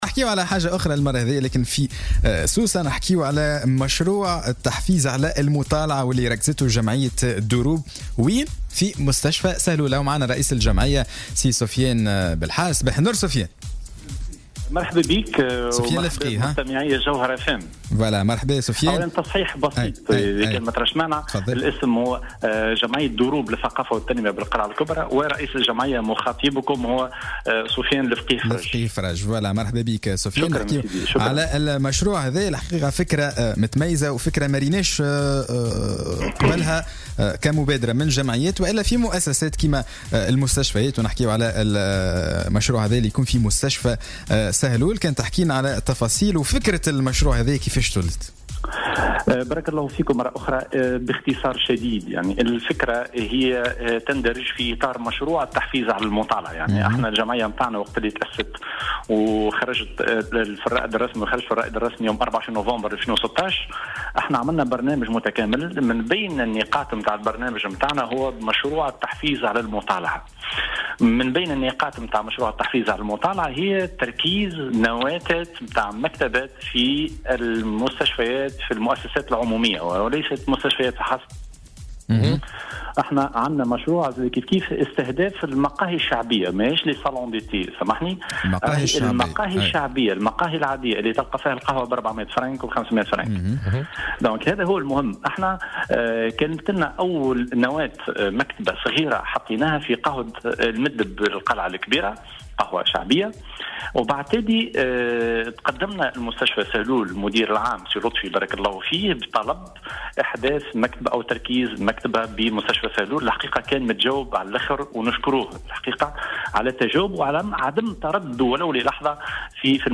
مداخلة له اليوم في برنامج "صباح الورد"